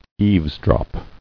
[eaves·drop]